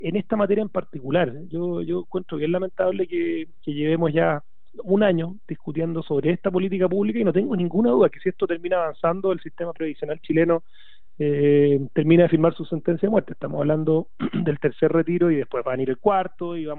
Así lo demostró Javier Macaya, presidente de Unión Demócrata Independiente, en entrevista con la radio Pauta.